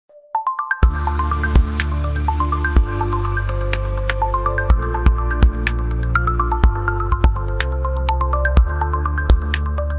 3D Sketch School Time Intro sound effects free download